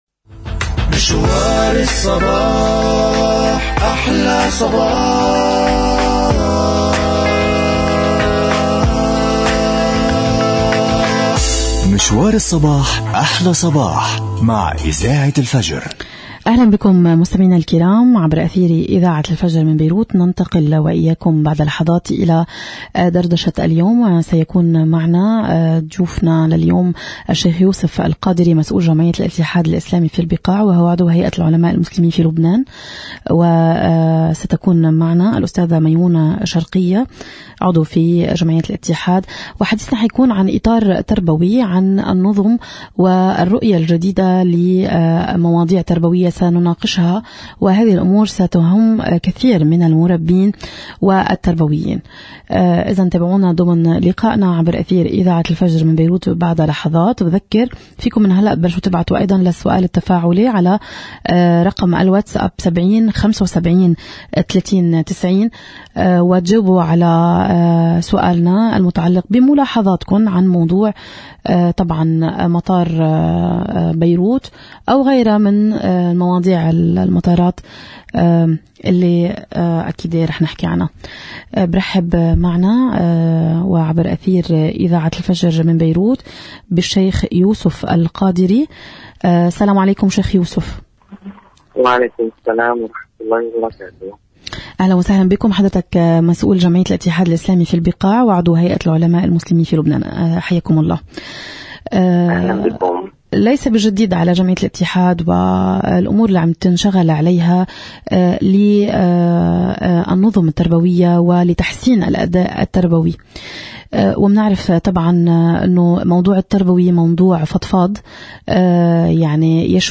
مقابلة حول (اليوم التدريبي للمتطوعين في جمعية الاتحاد الإسلامي)